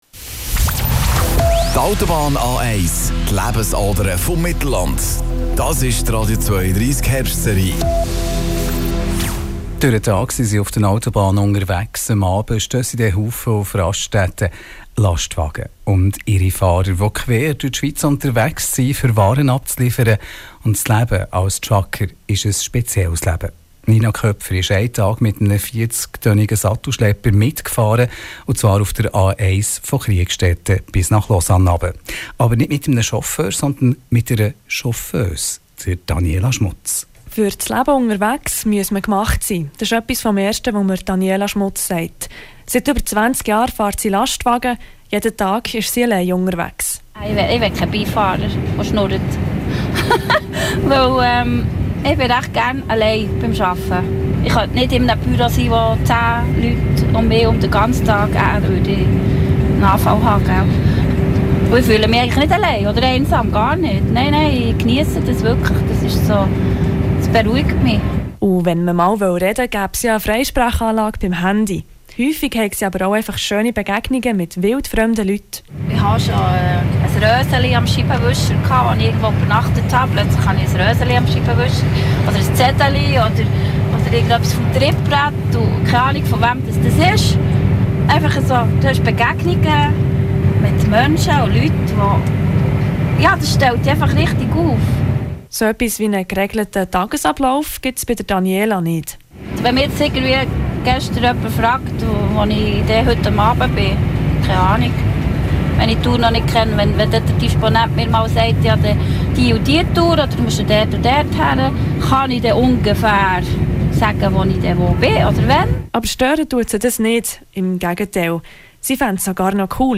Und ich durfte eine Chauffeuse in ihrem Sattelschlepper quer durch die Schweiz begleiten:
LKW-Chauffeuse.mp3